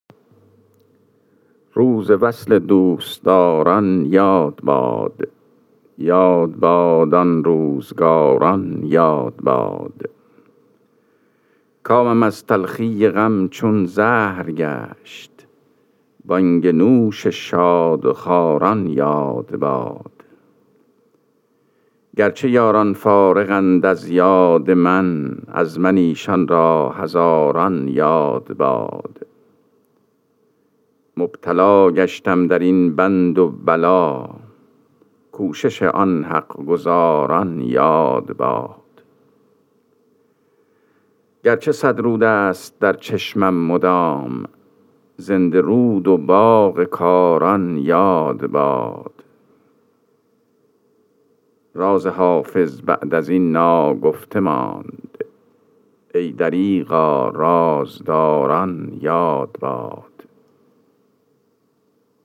خوانش غزل شماره ۱۰۳ دیوان حافظ